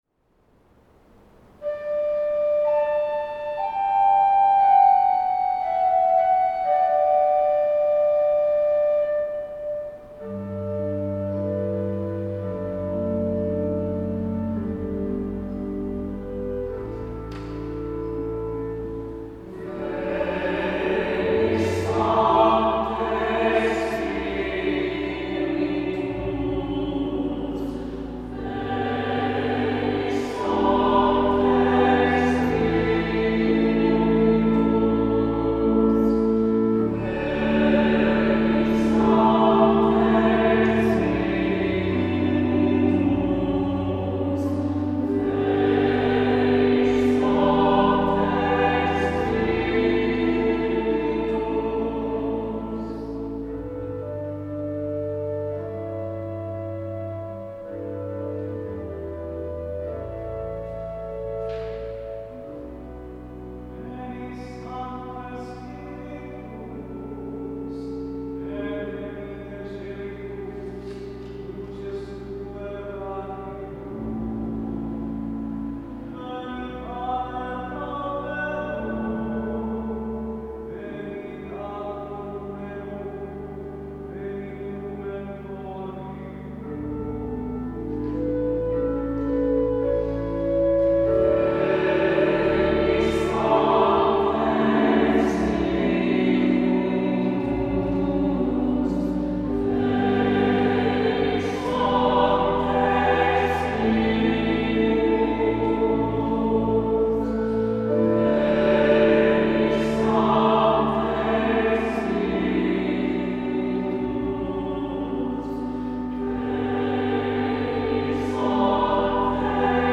Voicing: "SAB","Cantor","Assembly"